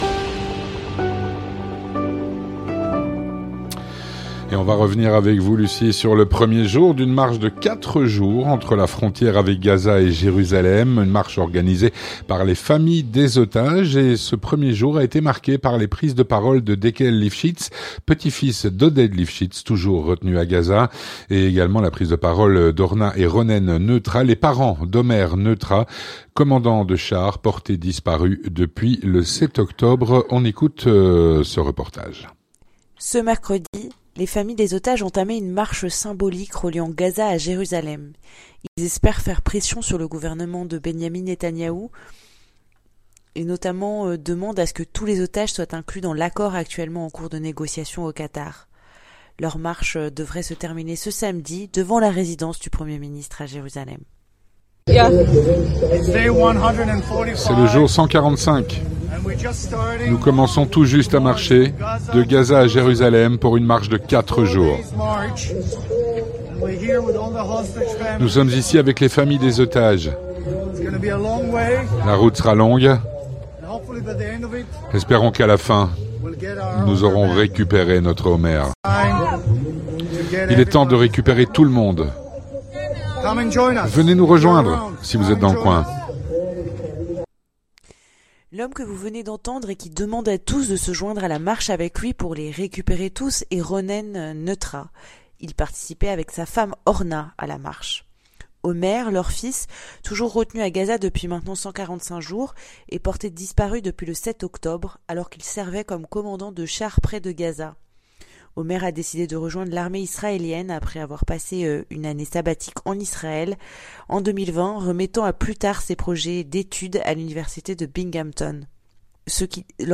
Témoignage - Une marche de 4 jours entre la frontière avec Gaza et Jérusalem organisée par les familles des otages.